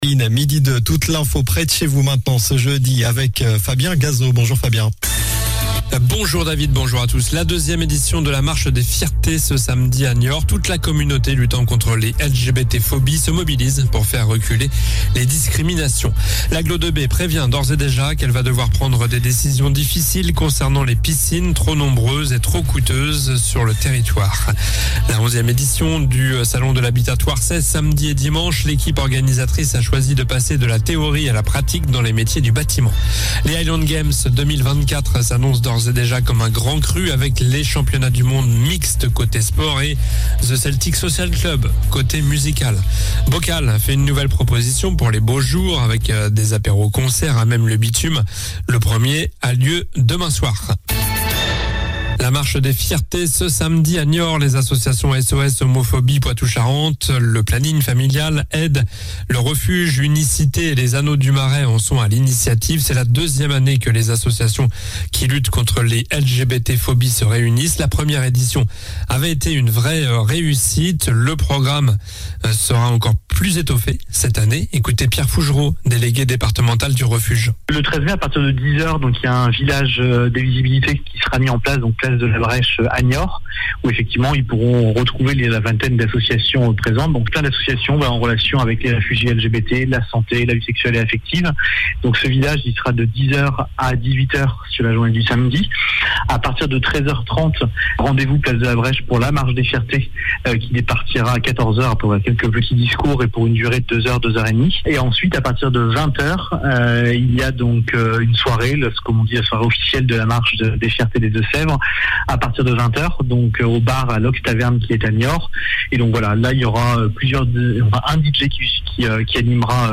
Journal du jeudi 11 mai (midi)